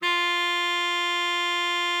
bari_sax_065.wav